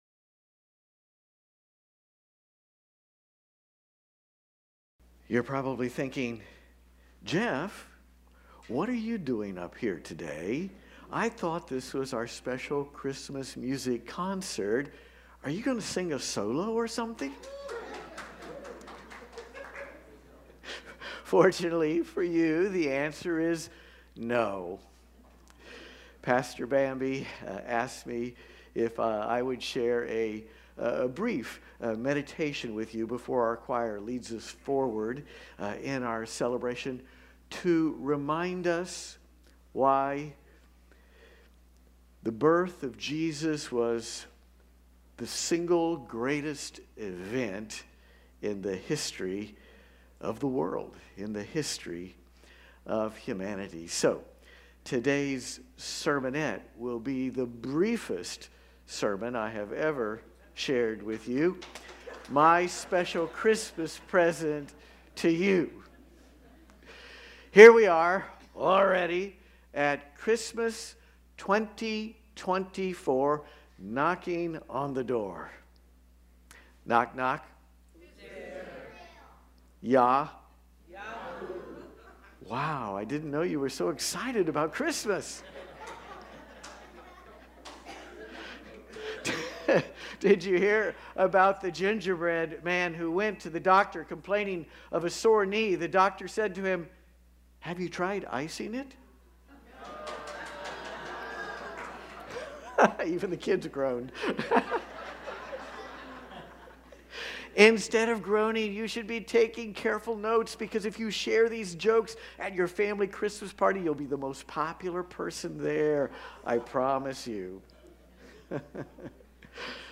Join us as we celebrate the birth of Jesus in a Christmas concert featuring our Christmas Choir, Praise Team, Soloists, and Band Ensemble!
LIVE Stream Replay Watch the entire Worship Service Sunday Headlines Weekly Bulletin Advent , Christ Birth , Christmas , Jesus’ birth Post a comment Cancel reply Δ This site uses Akismet to reduce spam.